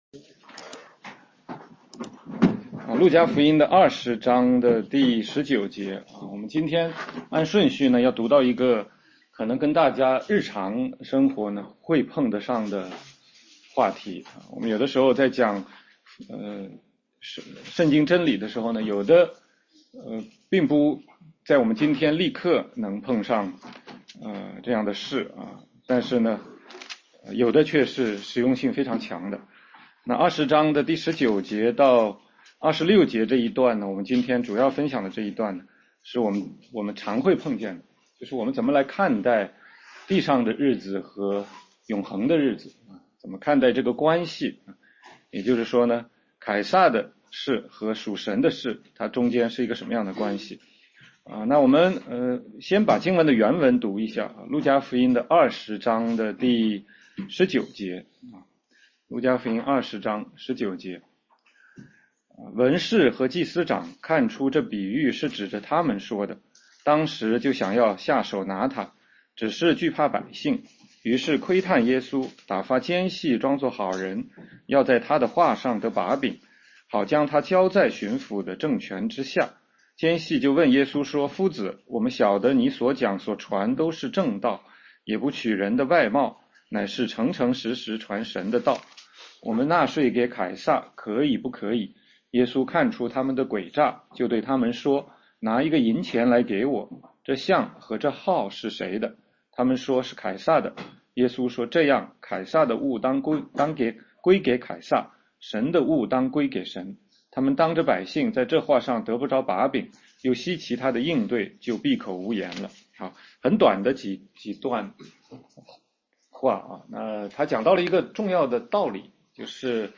全中文查经